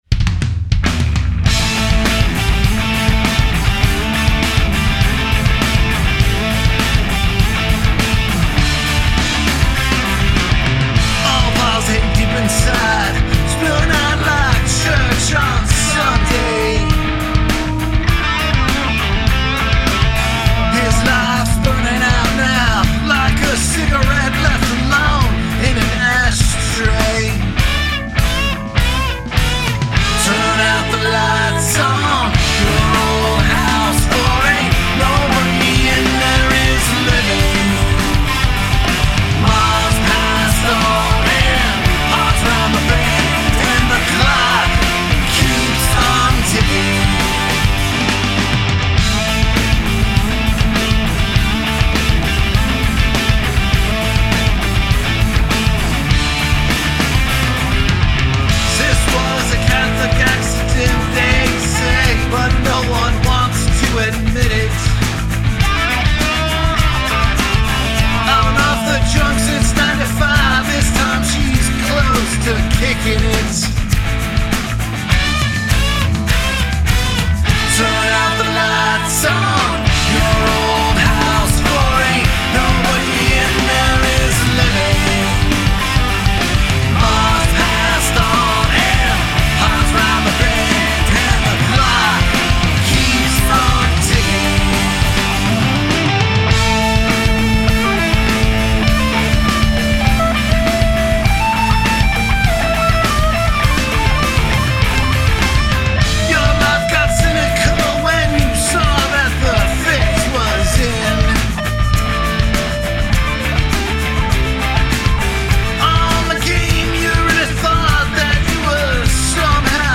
Rock & Roll Quintet